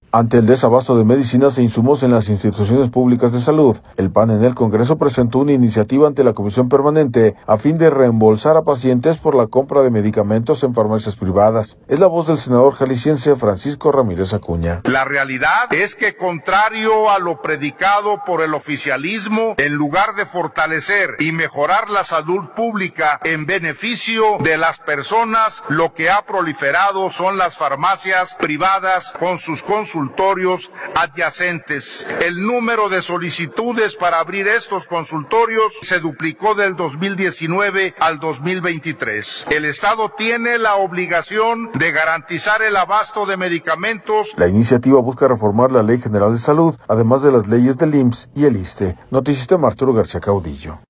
audio Ante el desabasto de medicinas e insumos en las instituciones públicas de Salud, el PAN en el Congreso presentó una iniciativa ante la Comisión Permanente a fin de reembolsar a pacientes por la compra de medicamentos en farmacias privadas. Es la voz del senador jalisciense, Francisco Ramírez Acuña.